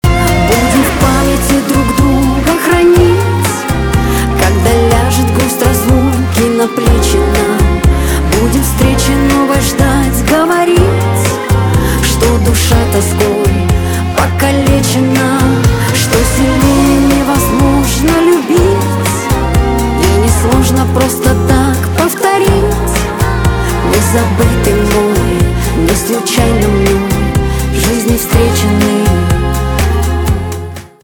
шансон
чувственные
барабаны , гитара